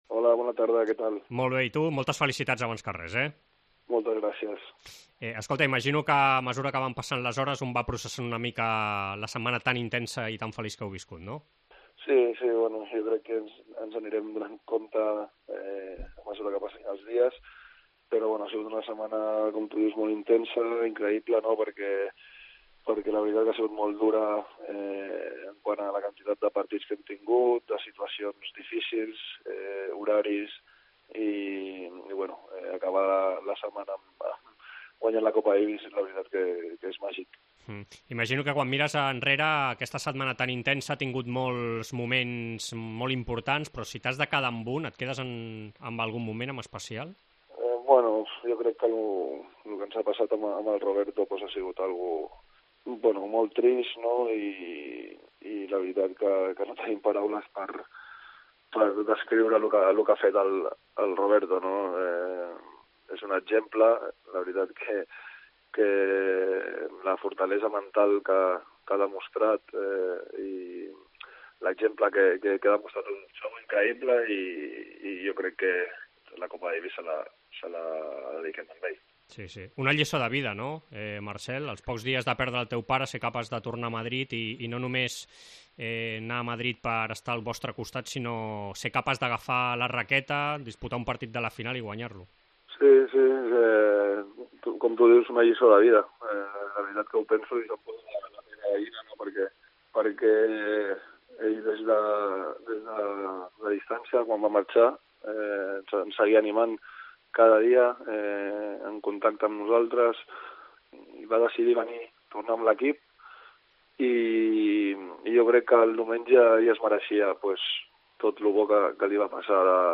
AUDIO: Entrevista al campió de Copa Davis, Marcel Granollers, parella de dobles de Rafa Nadal en la remontada de quarts contra l'Argentina